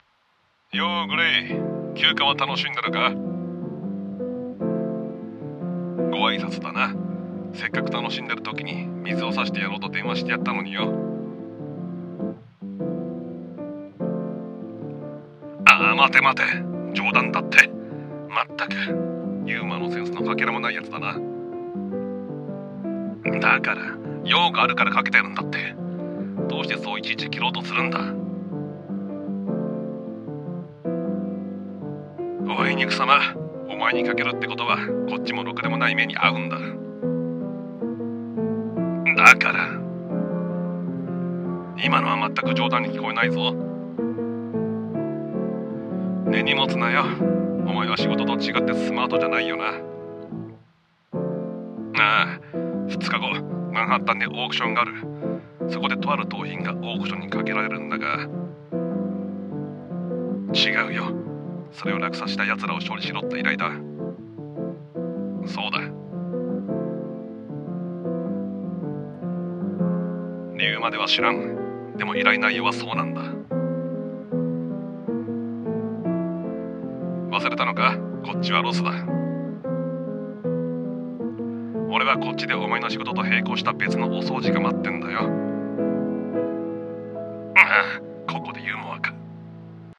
お仕事の依頼【二人用声劇台本】